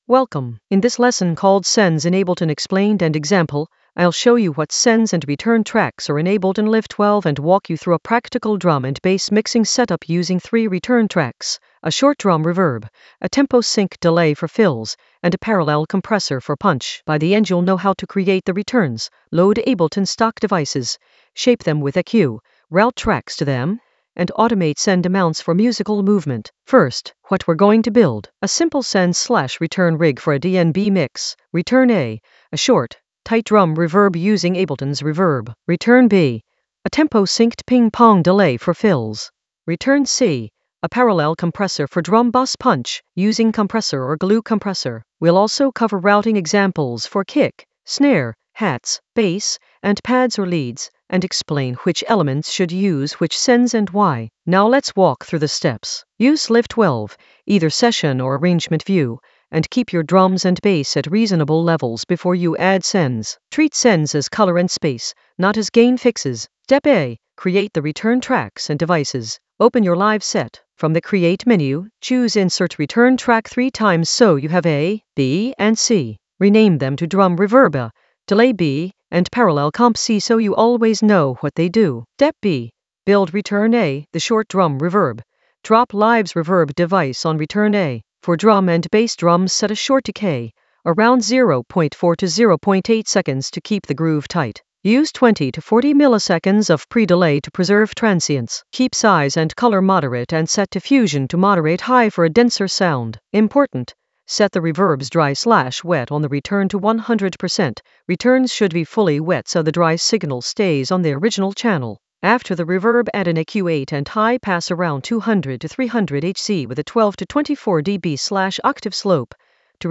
An AI-generated beginner Ableton lesson focused on Sends in Ableton explained and example in the Mixing area of drum and bass production.
Narrated lesson audio
The voice track includes the tutorial plus extra teacher commentary.